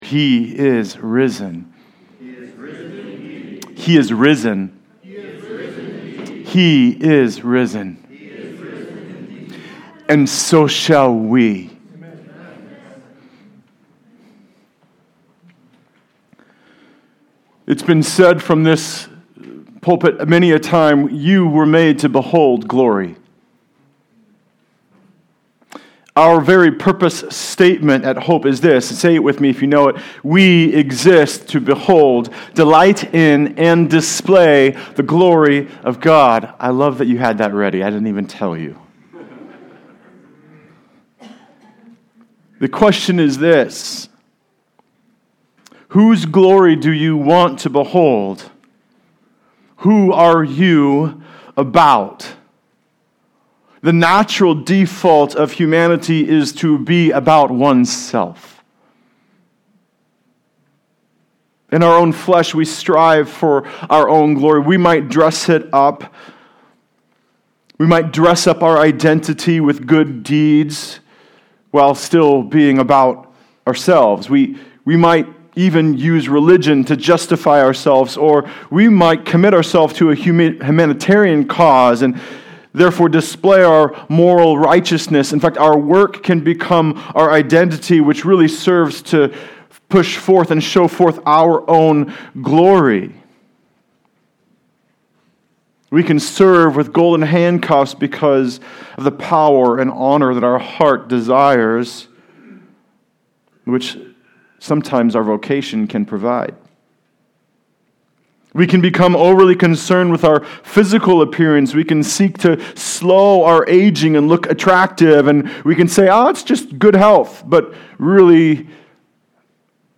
Passage: Colossians 3:1-4 Service Type: Sunday Service Related « Jesus is the Key to a Clean Heart Leave a Reply Cancel reply This site uses Akismet to reduce spam.